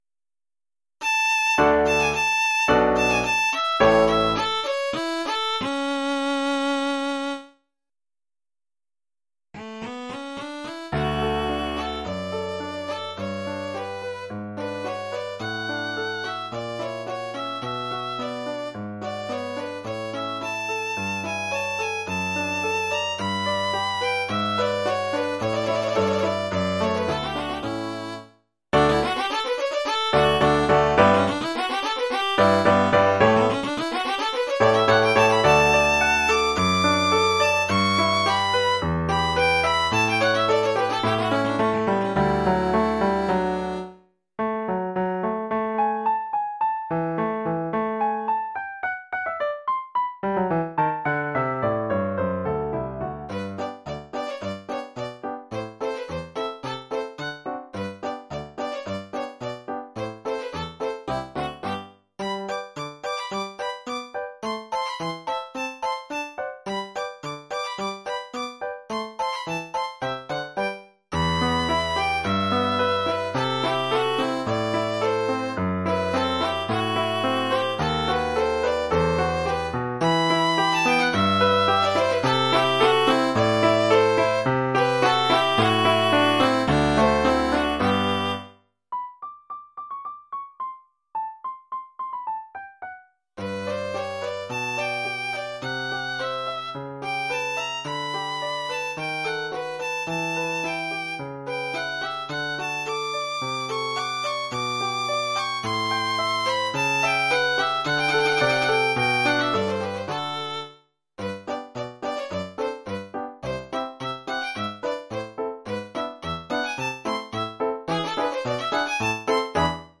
Formule instrumentale : Violon et piano
Oeuvre pour violon avec
accompagnement de piano.